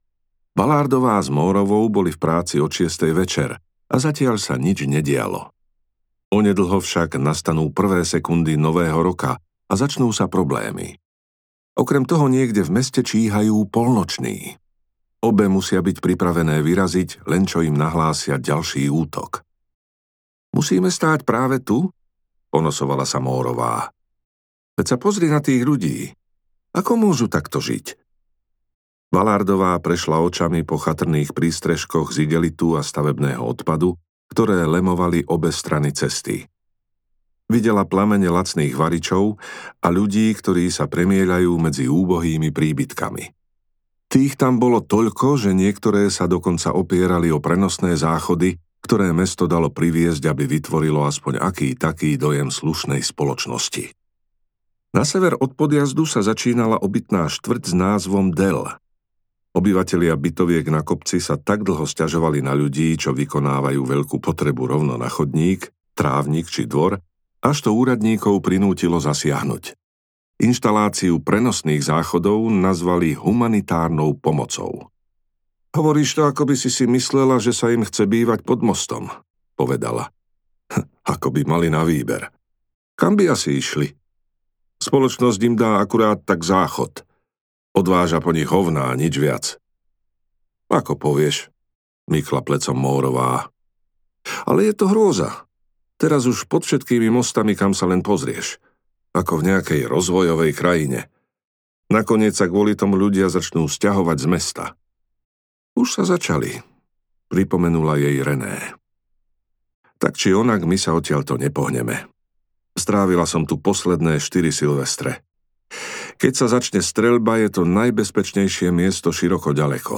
Hodina duchov audiokniha
Ukázka z knihy